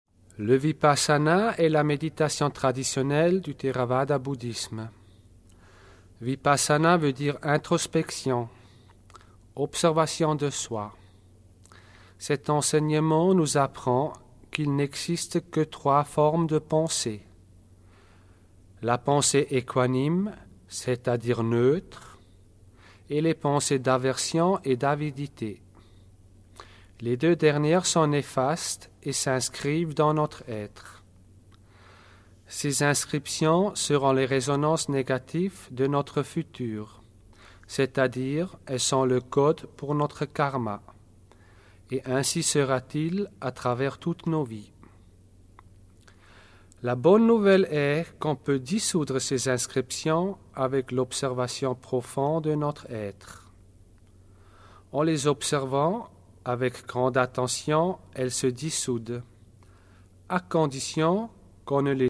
Cette méditation est sans musique afin de mettre toute concentration sur le travail